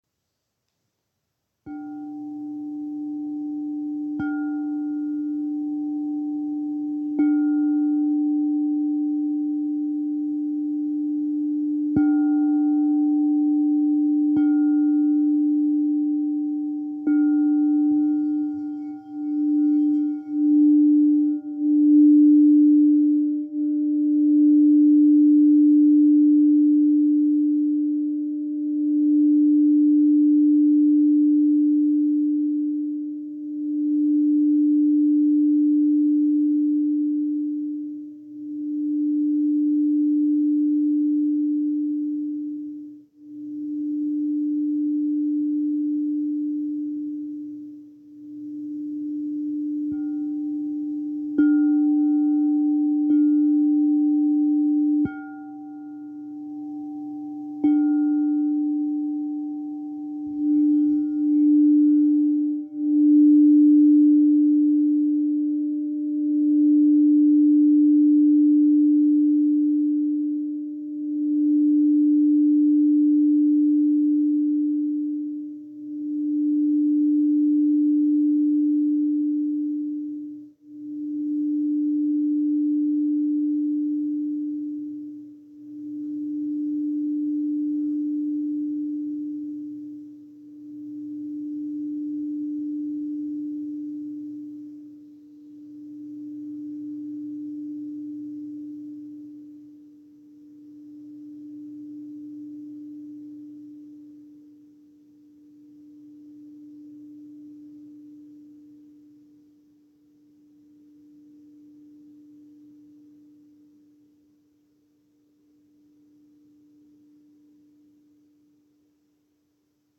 "Zpívající" Křišťálové mísy
Mísa tón A velikost 9" (23cm)
Ukázka mísa A